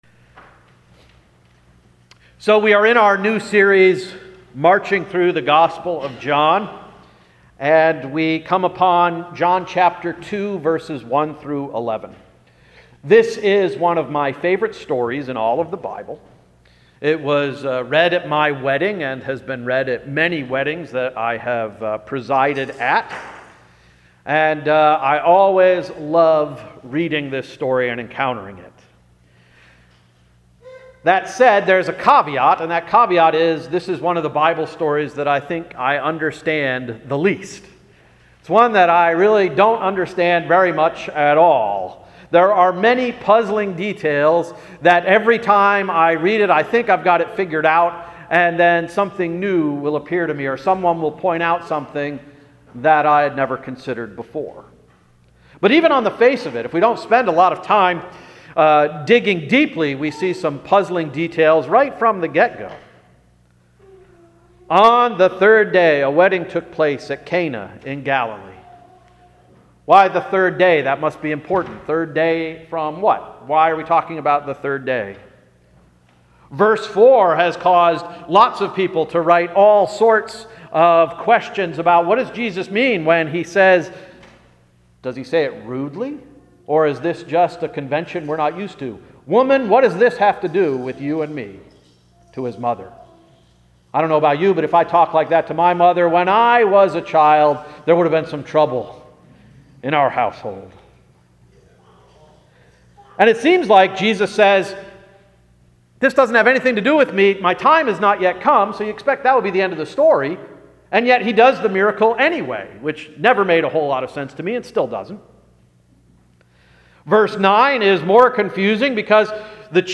August 27, 2017 Sermon– “Listen to Your Mother!”